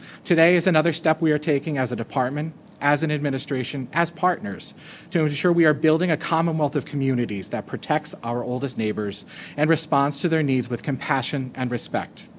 The announcement was made yesterday at a news conference held by the Department of Aging at the KCAC.
1-7-26-jason-kavulich-1.mp3